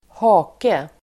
Uttal: [²h'a:ke]